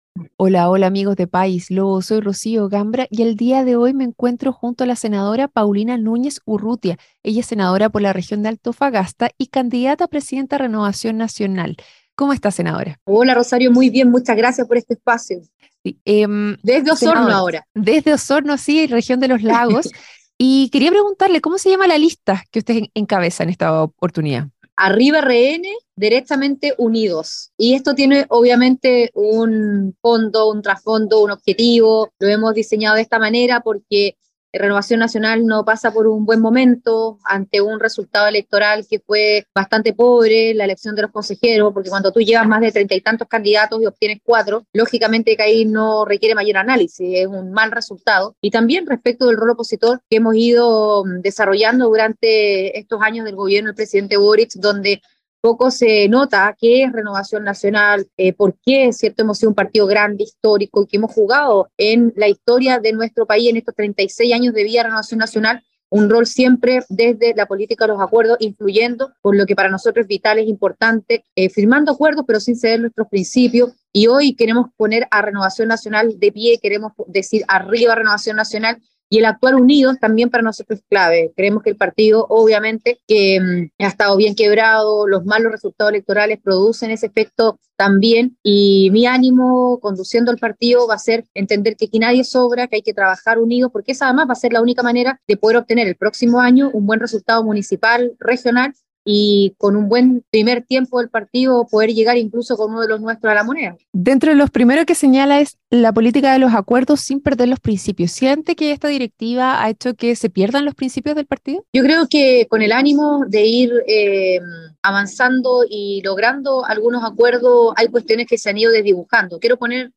En su emisión más reciente, conversó con la Senadora Paulina Núñez Urrutia que por estos días busca ser presidenta del Partido Renovación Nacional.